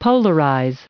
598_polarize.ogg